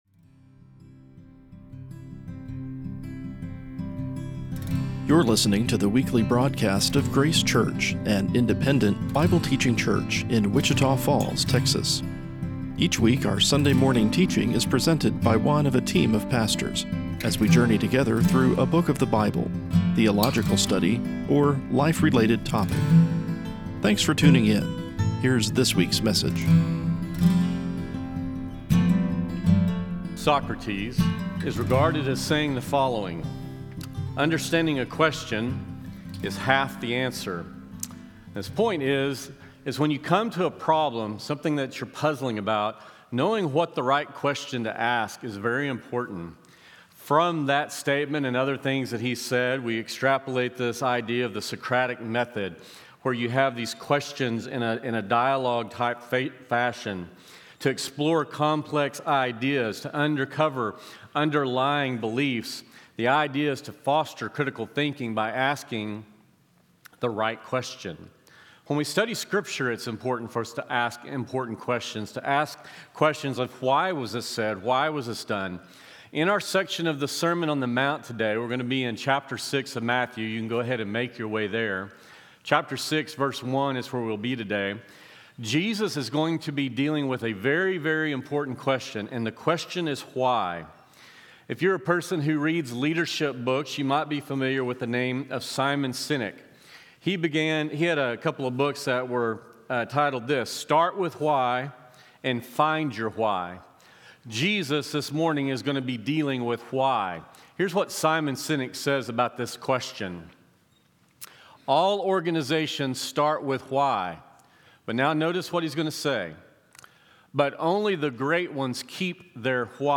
A weekly podcast of Sunday morning Bible teaching from Grace Church in Wichita Falls, Texas.